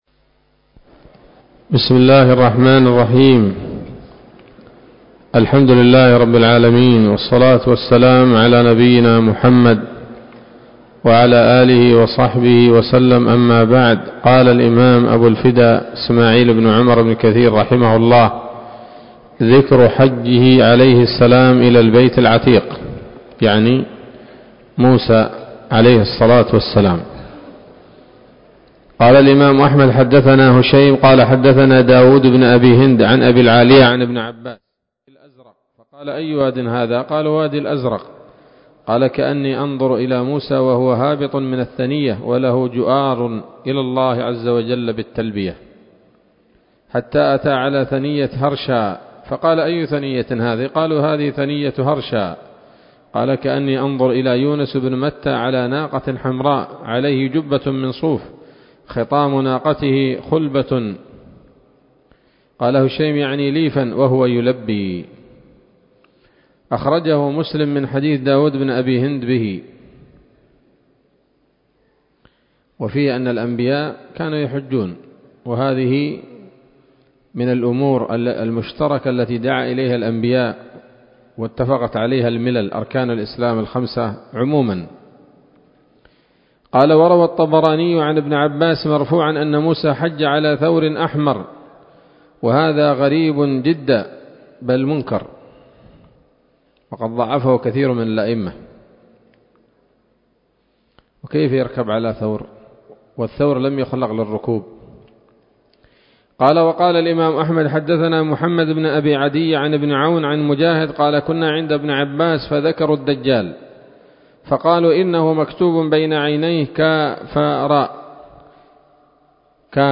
‌‌الدرس السادس بعد المائة من قصص الأنبياء لابن كثير رحمه الله تعالى